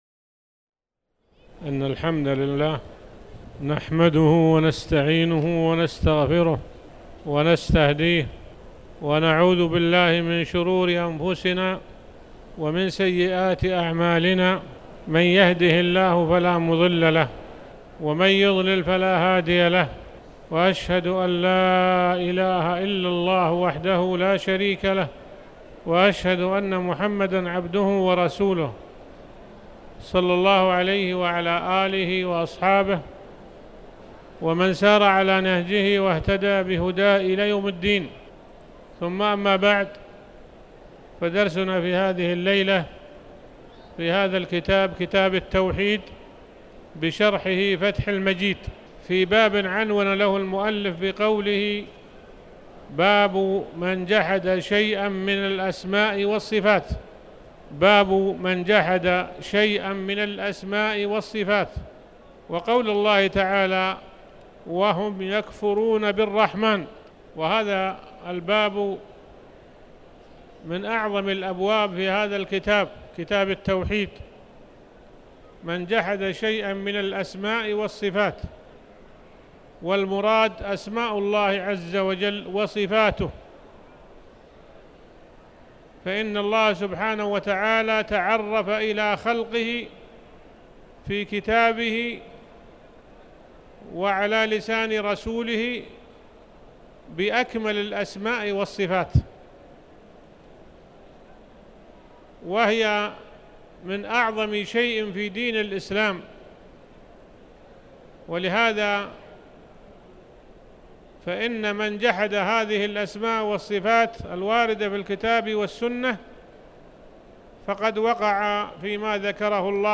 تاريخ النشر ٤ جمادى الآخرة ١٤٣٩ هـ المكان: المسجد الحرام الشيخ